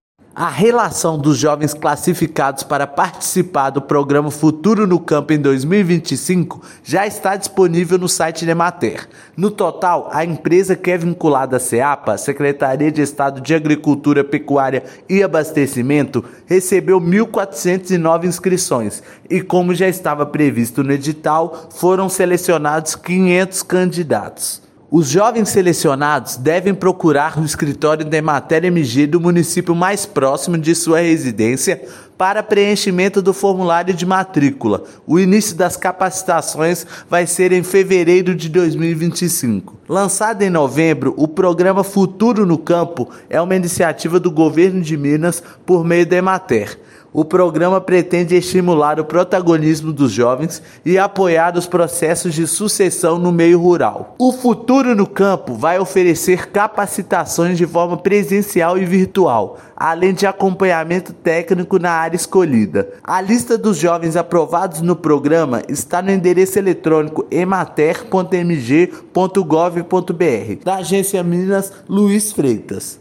Relação dos jovens selecionados e os nomes da lista de espera estão disponíveis no site da Emater-MG. Ouça matéria de rádio.